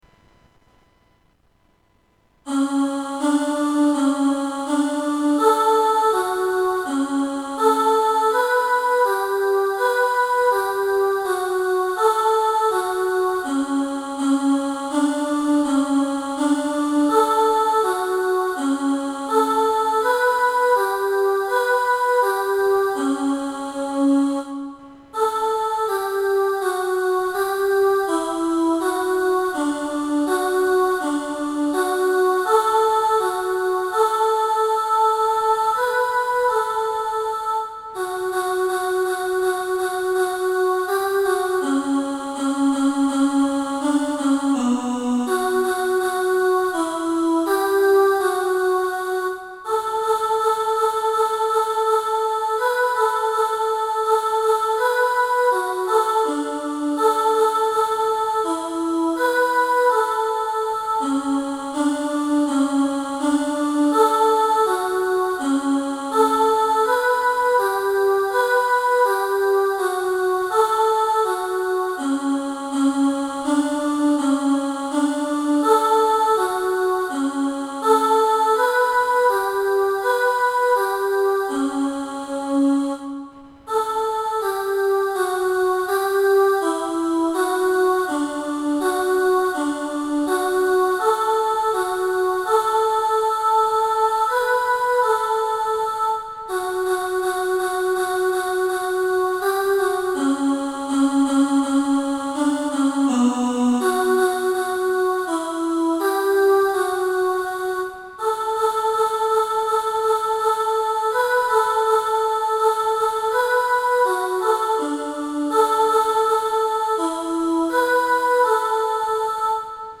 "RTW Alto".
Rule-The-World-Alto-version-2.mp3